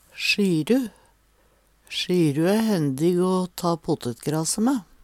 sjyru, sigg - Numedalsmål (en-US)